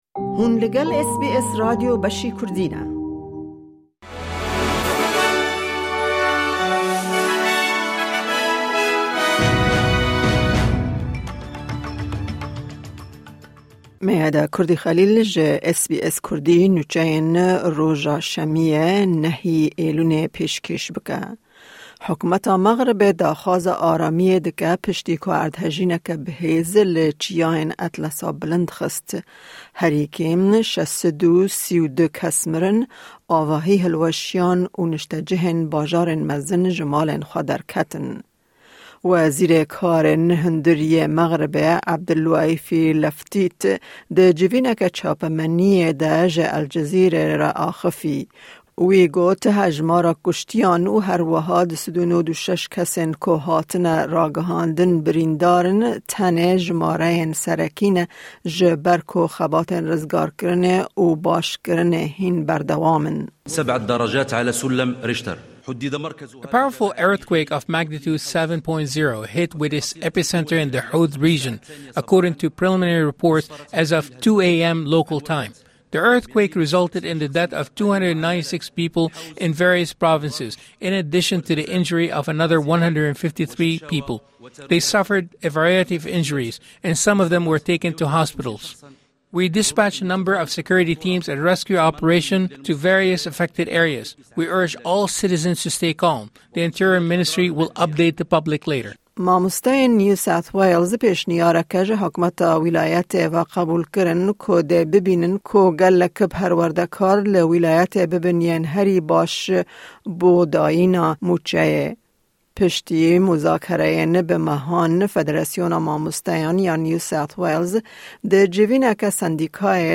Kurte Nûçeyên roja Şemiyê 9î Îlona 2023